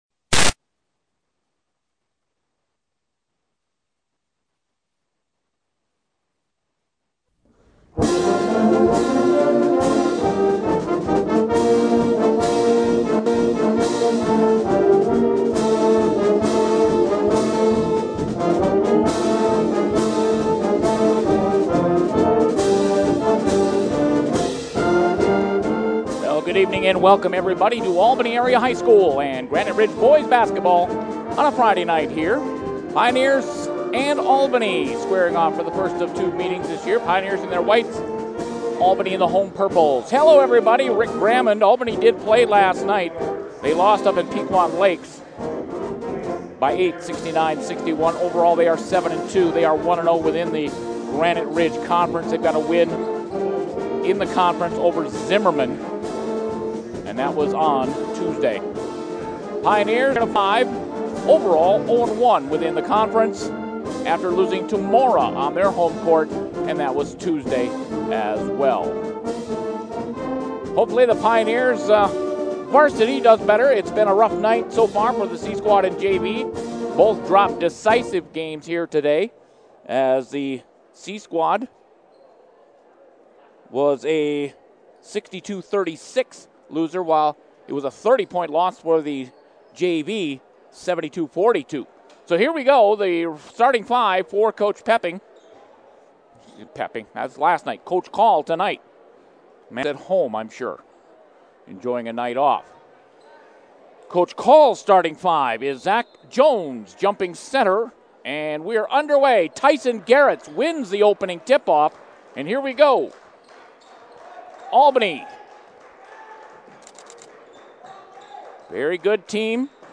Pierz Pioneers at Albany Huskies Boys Basketball 2022